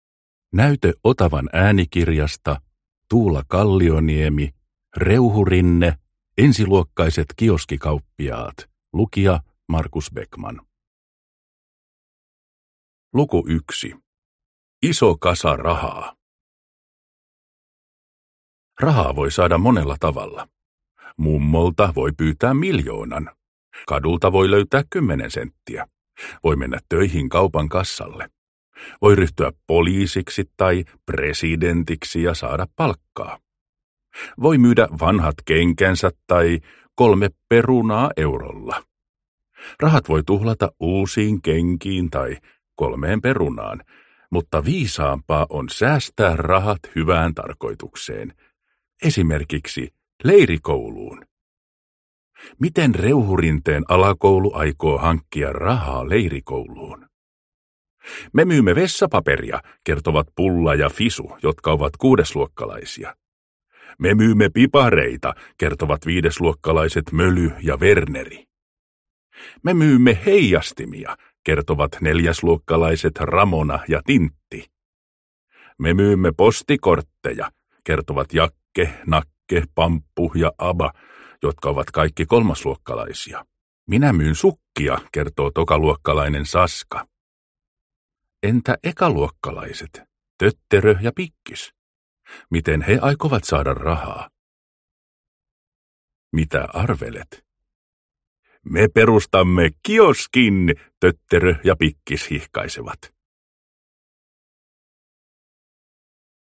Ensiluokkaiset kioskikauppiaat – Ljudbok – Laddas ner
Hauskoja Reuhurinne-tarinoita äänikirjoina!